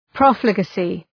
Προφορά
{‘prɔ:flı,gæsı}